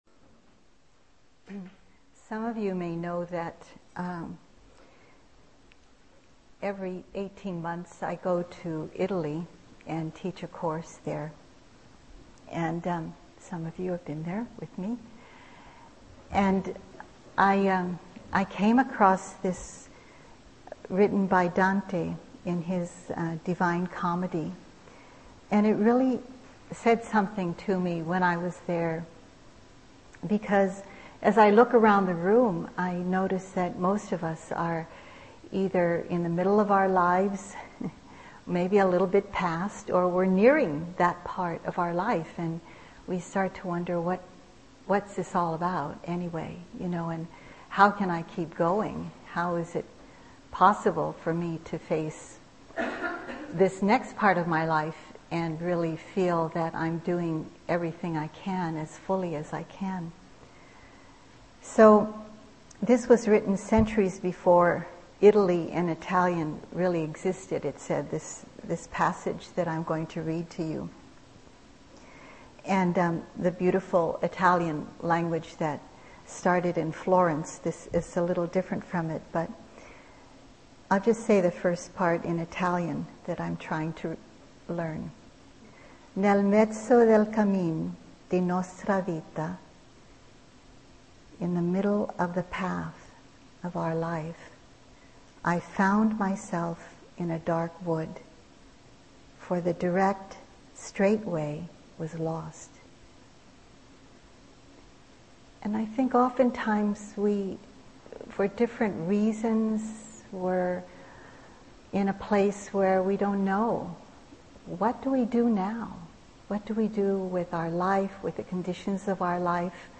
2014-03-07 Venue: Seattle Insight Meditation Center